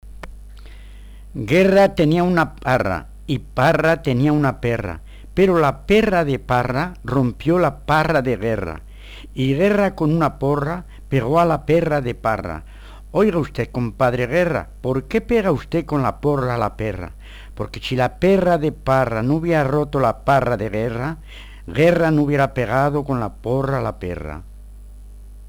trabalenguas